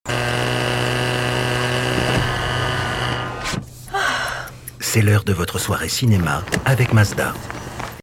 French. Actor, deep, action-packed!